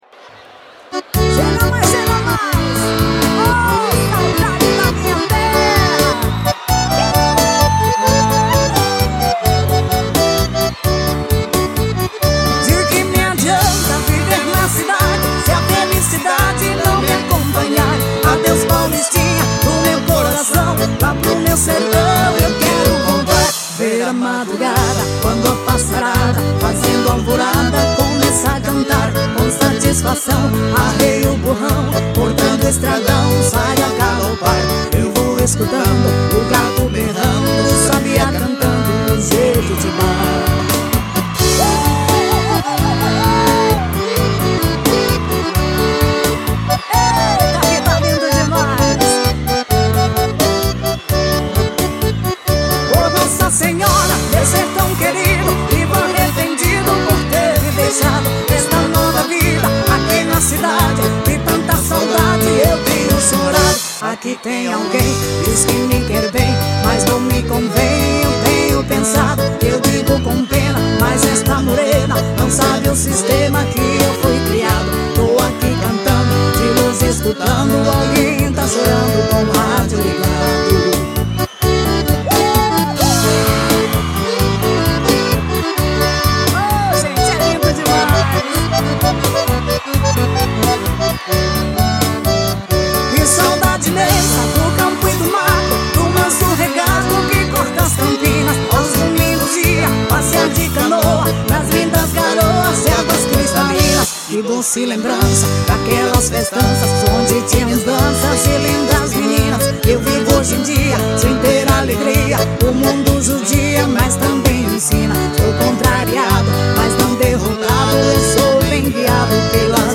Chamamé.